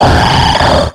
Cri de Kaorine dans Pokémon X et Y.